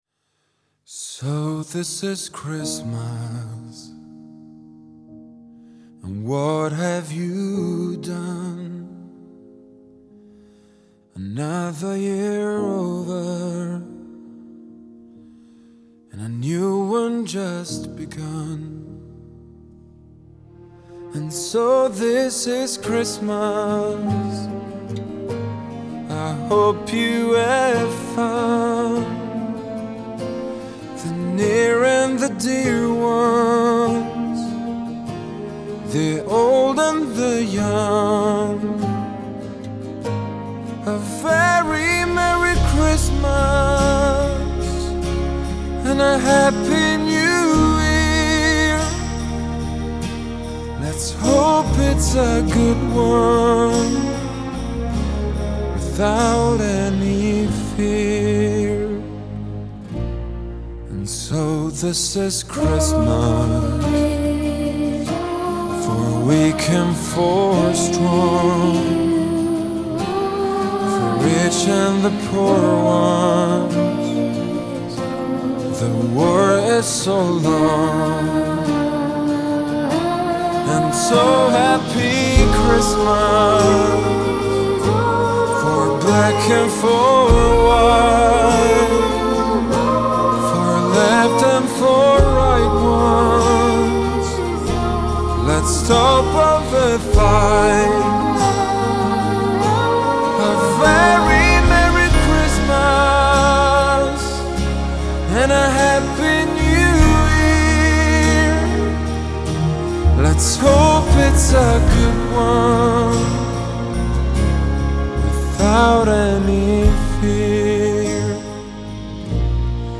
Genre : Christmas Music